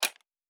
pgs/Assets/Audio/Sci-Fi Sounds/Mechanical/Device Toggle 07.wav
Device Toggle 07.wav